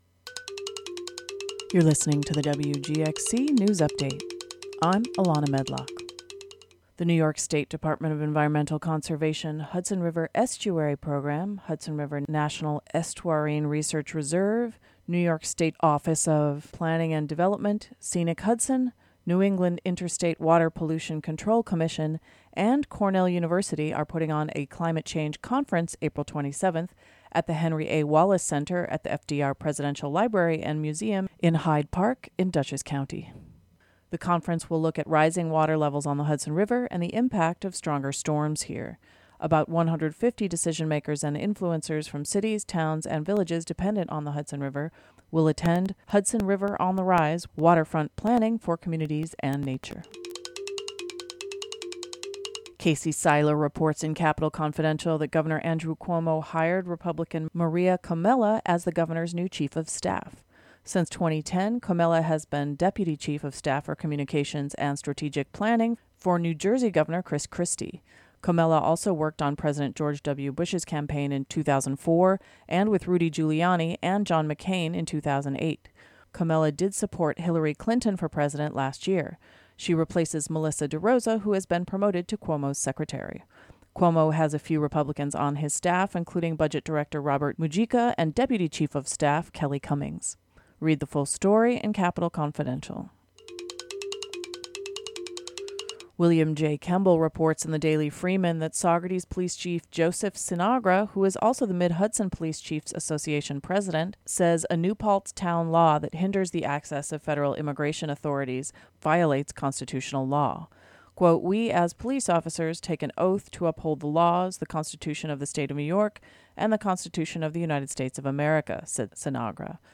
Click here to listen to WGXC's Congressional report, a look back at the week in news for Rep. John Faso (R-Kinderhook).